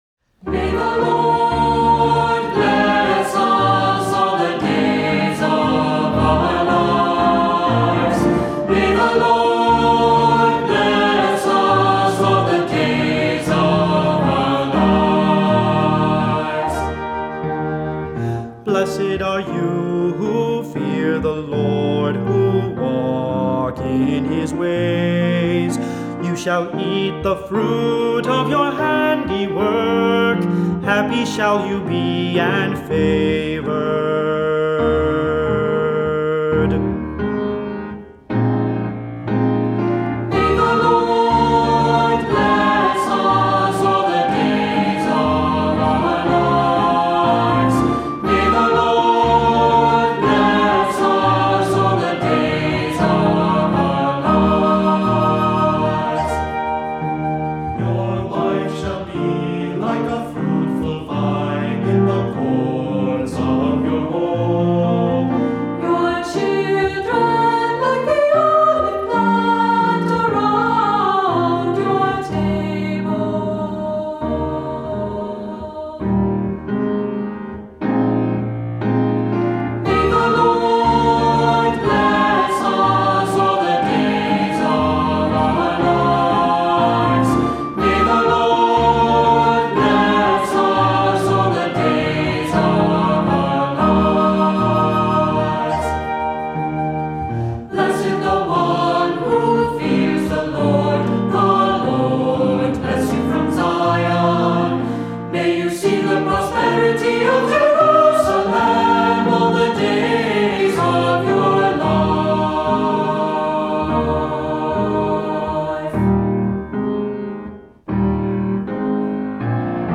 Voicing: Cantor or Unison; Assembly